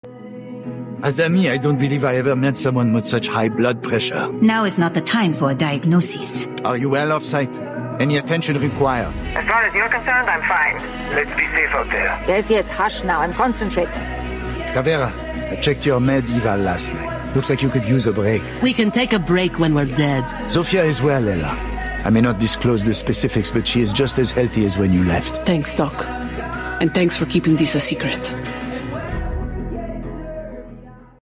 Almost every voice line he sound effects free download